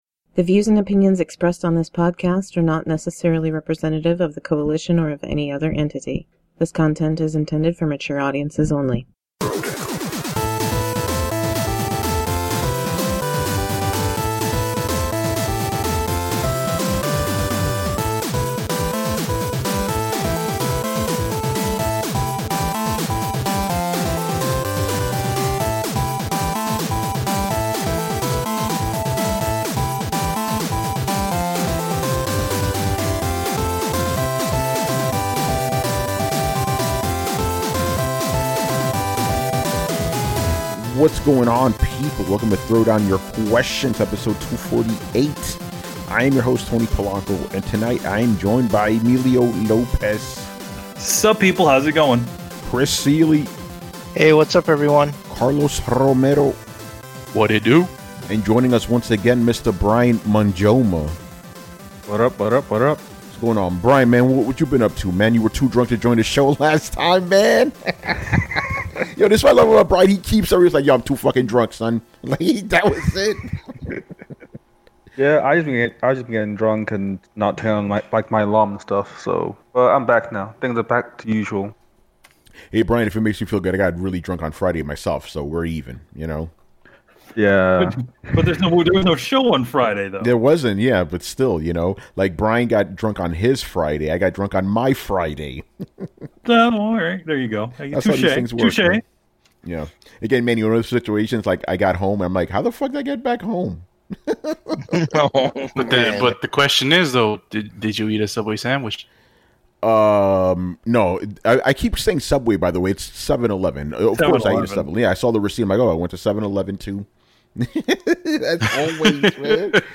On this show, we answer all of your Video Game related questions.